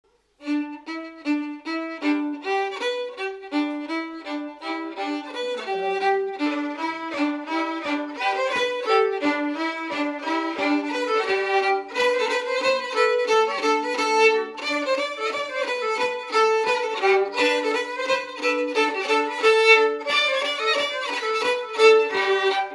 Chants brefs - A danser
danse : scottish (autres)
Pièce musicale inédite